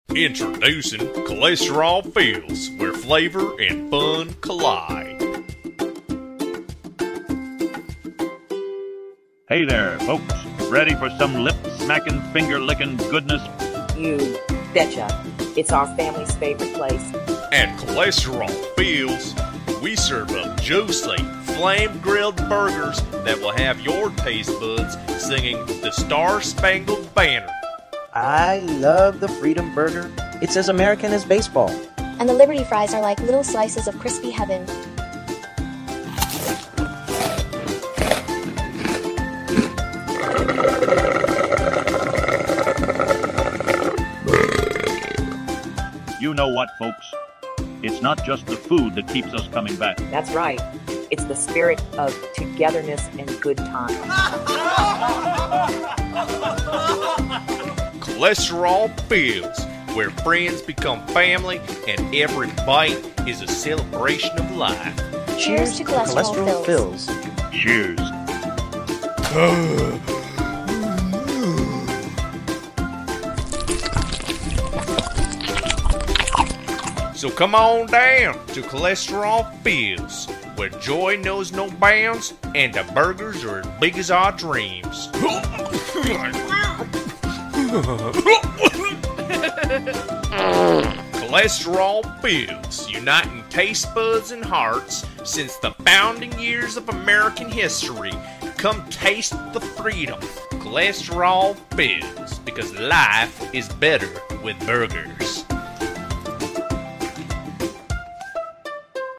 Voices: Genny